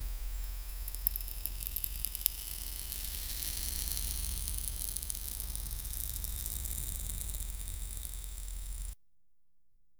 Sparkling, with a magical tone. 0:02 brillos suaves y petalos cayendo 0:10 Create a short audio cue.
brillos-suaves-y-petalos--uqgnqx5h.wav